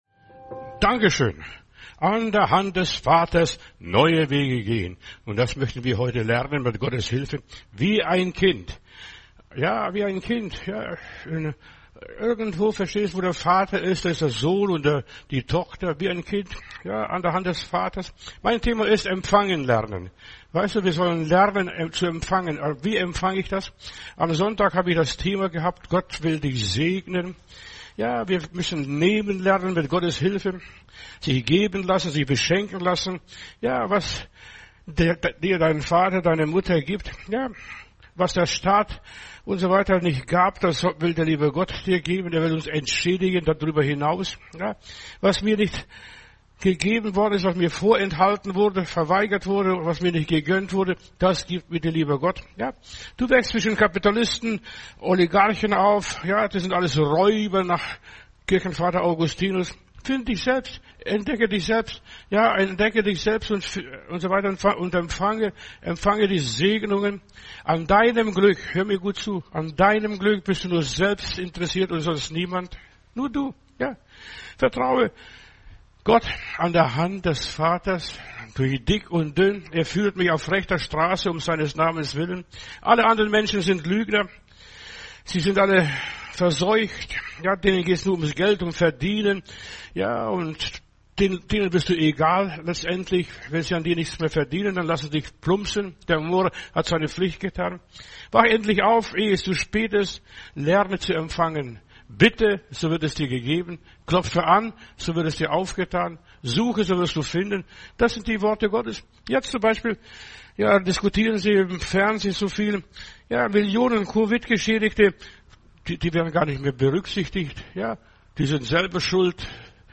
Predigt herunterladen: Audio 2025-03-12 Empfangen lernen Video Empfangen lernen